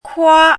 “姱”读音
kuā
国际音标：kʰuɑ˥;/xu˥˧
kuā.mp3